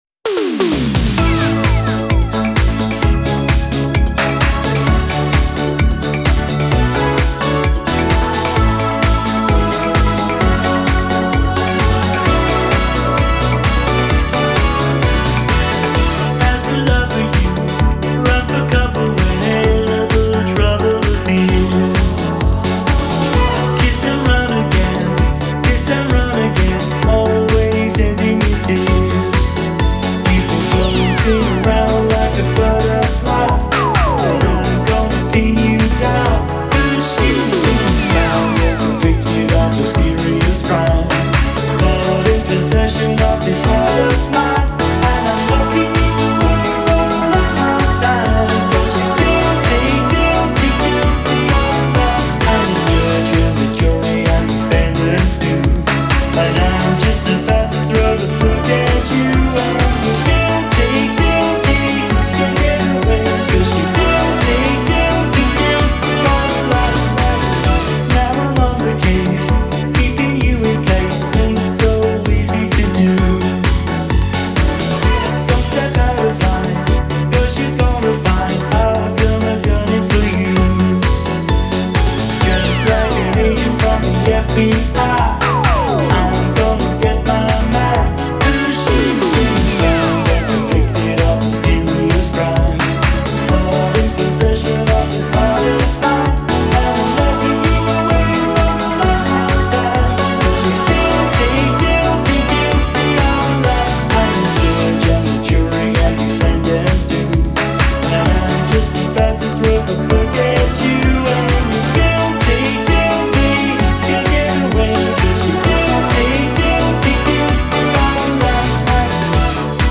UK pop artist
Radio Edit